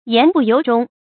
注音：ㄧㄢˊ ㄅㄨˋ ㄧㄡˊ ㄓㄨㄙ
言不由衷的讀法